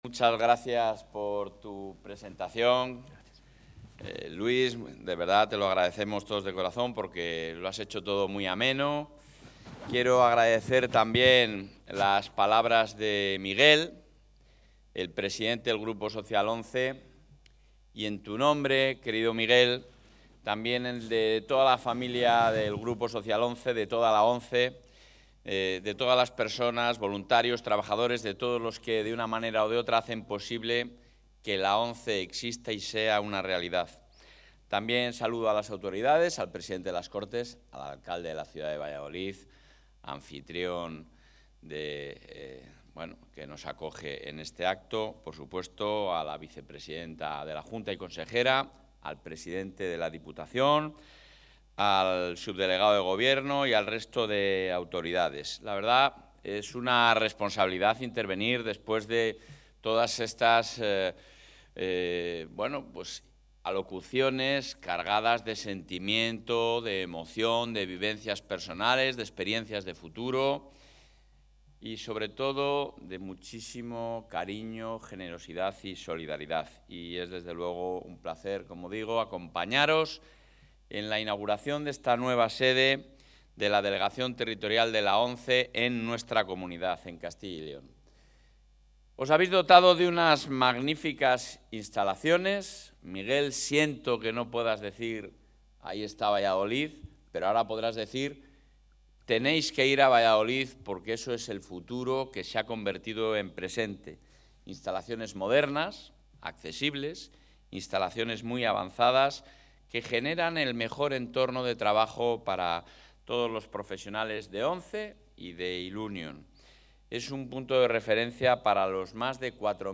Intervención del presidente de la Junta.
El presidente de la Junta de Castilla y León ha participado hoy en la inauguración de la nueva sede de la Delegación Territorial de la ONCE en Castilla y León, ubicada en Valladolid, donde ha señalado el compromiso del Ejecutivo autonómico con una igualdad efectiva de las personas que tienen algún tipo de capacidad diferente.